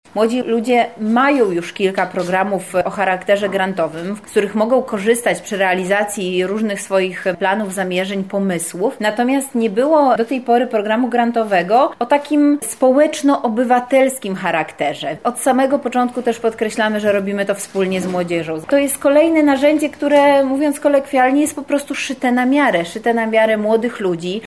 -mówi Beata Stepaniuk-Kuśmierzak, Zastępczyni Prezydenta Miasta Lublin ds. Kultury, Sportu i Partycypacji.